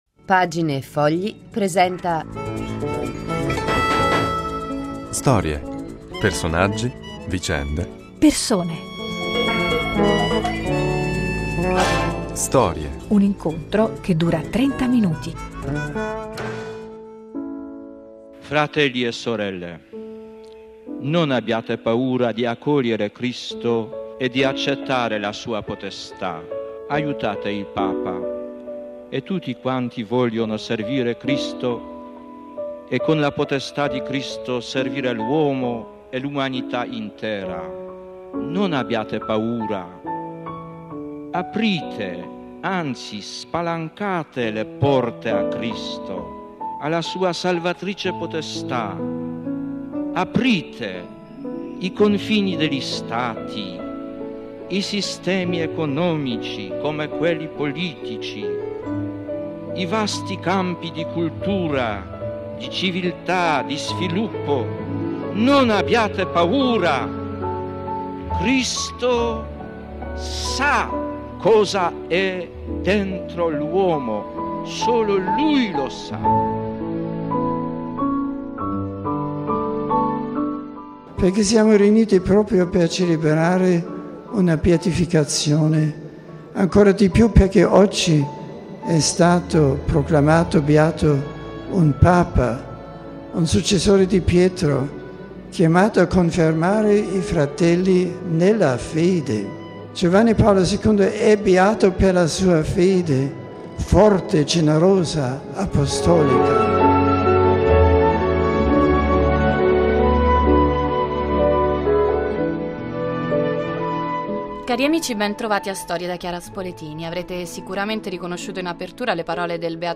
Melodie, ricordi, presente e futuro di un ragazzo che ha fatto della sua voce un'emozione per gli altri e che con gli occhi bene aperti su ciò che lo circonda sostiene che tendere la sua mano verso l'altro sia la sua più grande conquista di libertà.